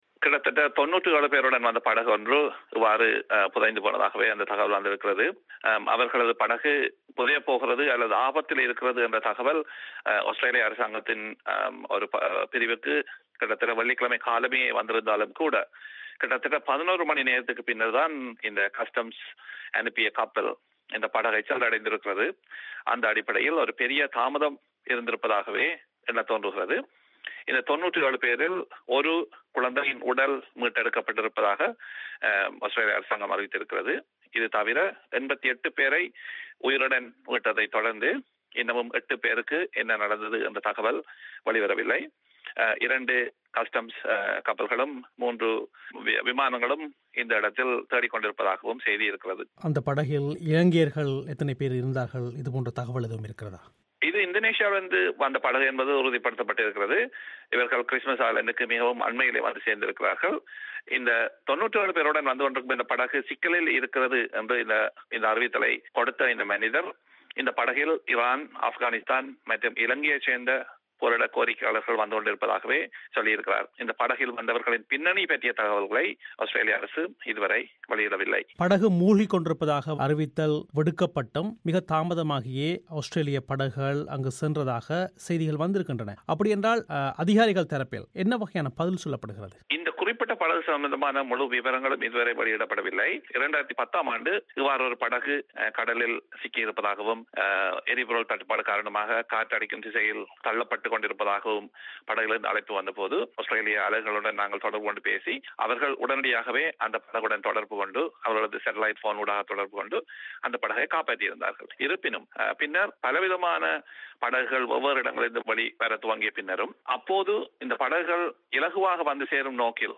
செவ்வி